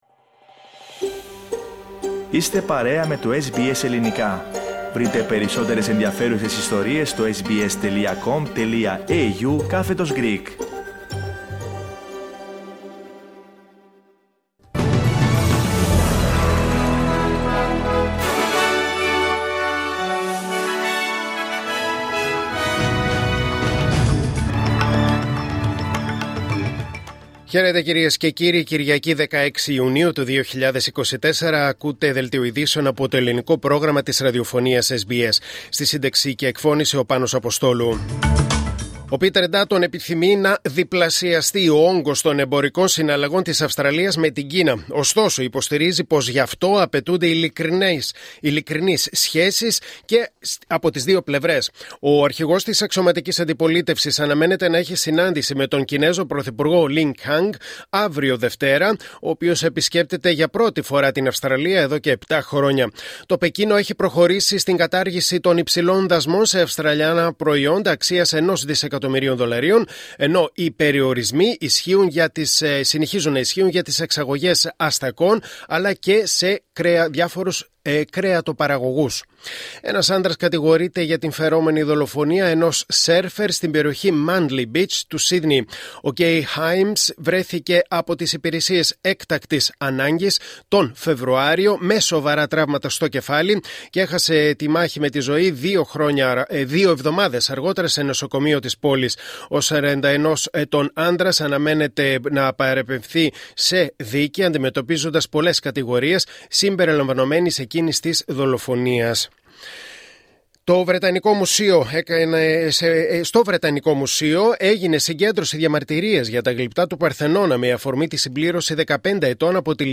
Δελτίο Ειδήσεων Κυριακής 16 Ιουνίου 2024